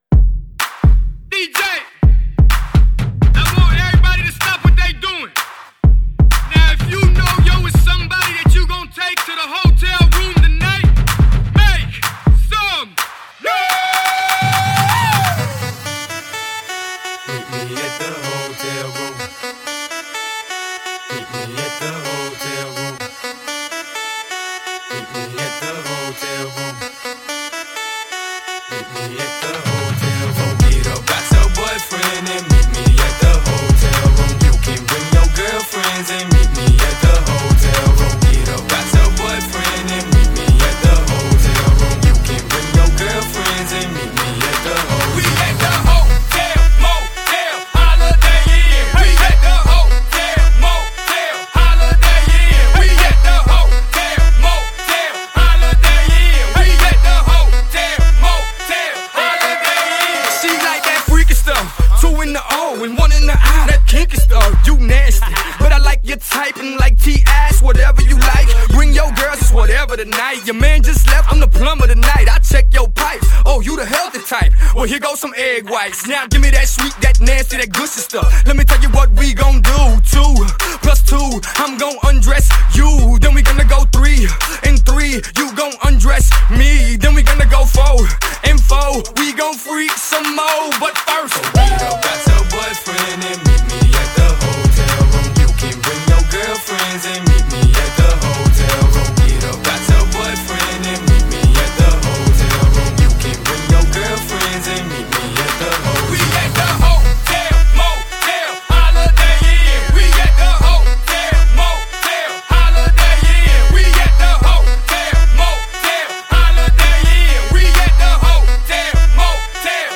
ژانر: هیپ هاپ - پاپ رپ- پاپ